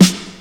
• Classic Good Rap Snare Sample F# Key 166.wav
Royality free steel snare drum tuned to the F# note. Loudest frequency: 1677Hz
classic-good-rap-snare-sample-f-sharp-key-166-vIj.wav